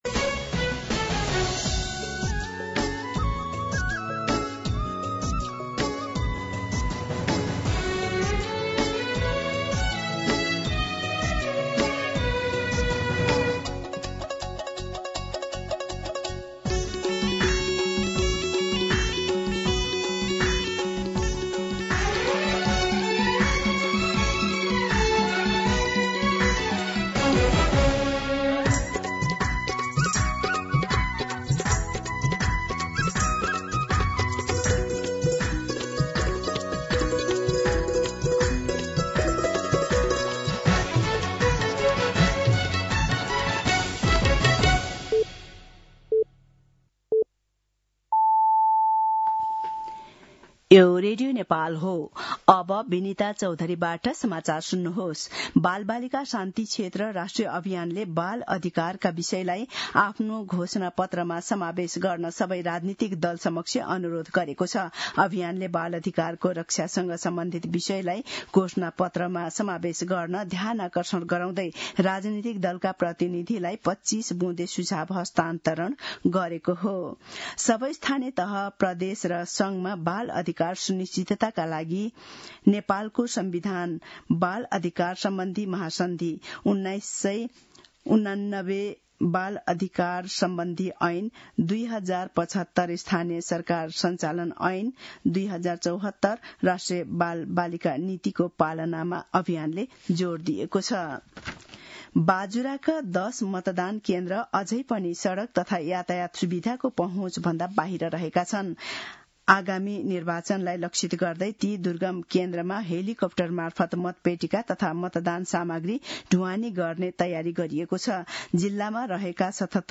मध्यान्ह १२ बजेको नेपाली समाचार : २९ माघ , २०८२